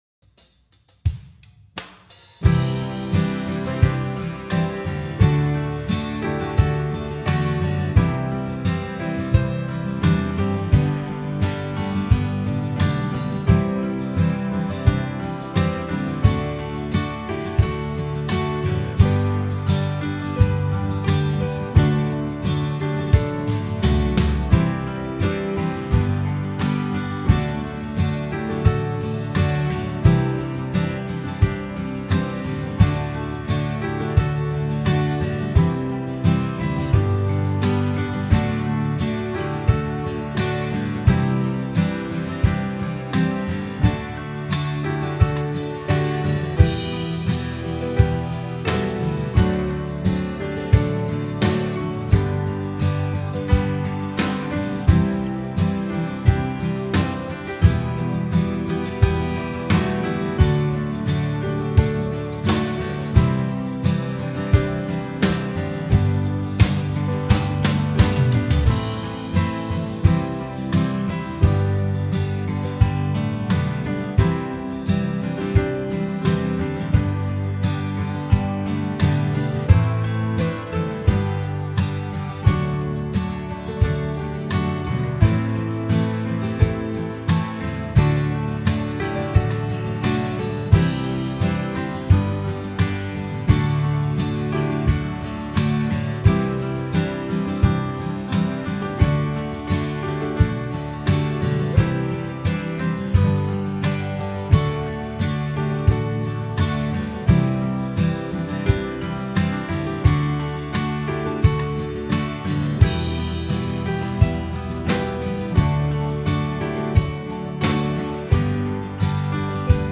Home > Music > Pop > Bright > Smooth > Medium